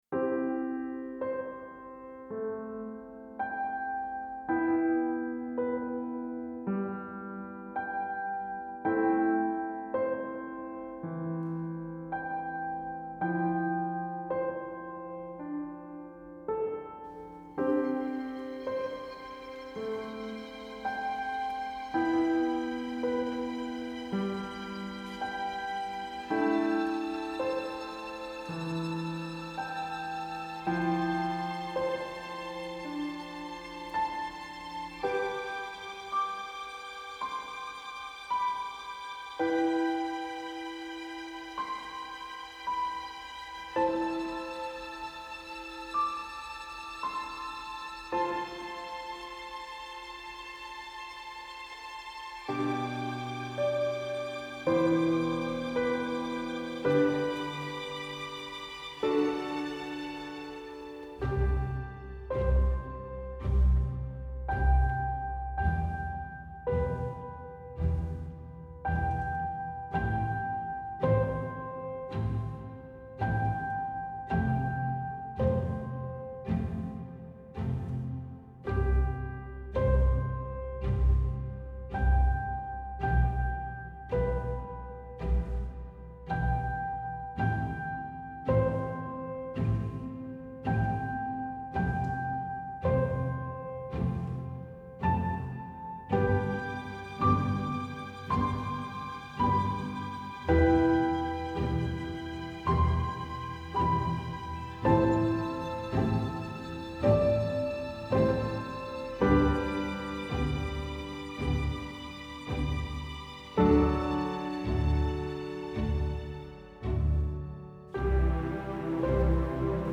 موسیقی فیلم , ساندترک , موسیقی بی‌کلام
Epic